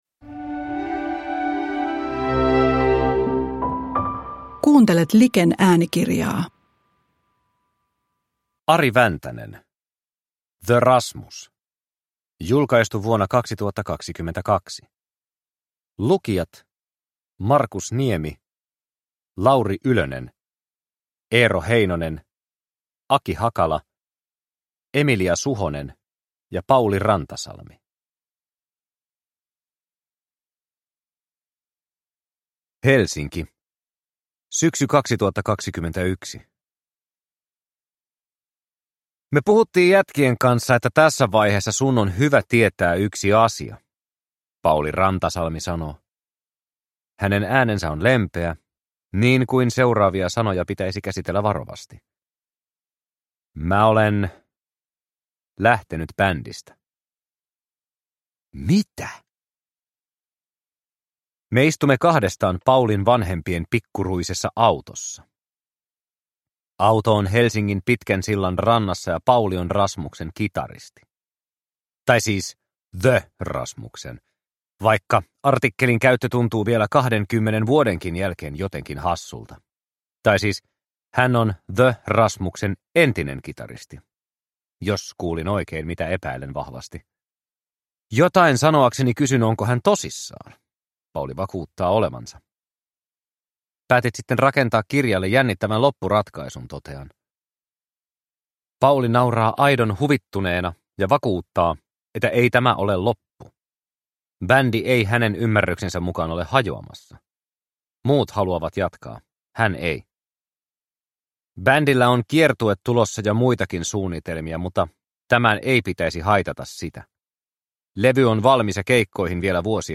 The Rasmus – Ljudbok – Laddas ner